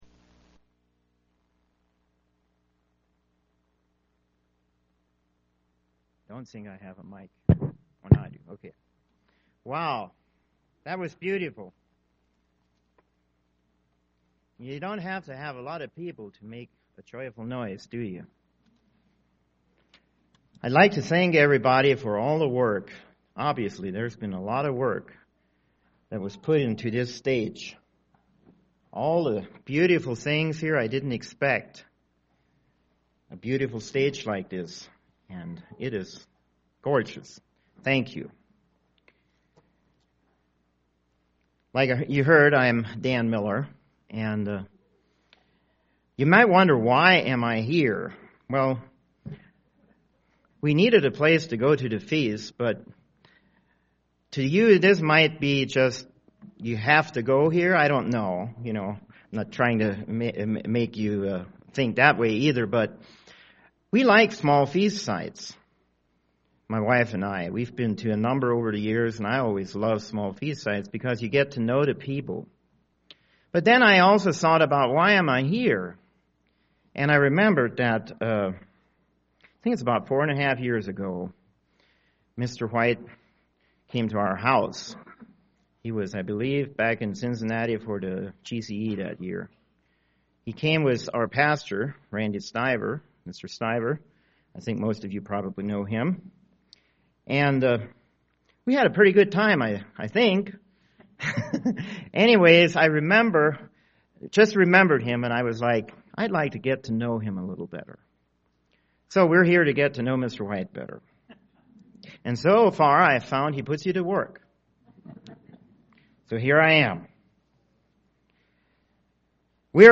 This sermon was given at the Bigfork, Montana 2014 Feast site.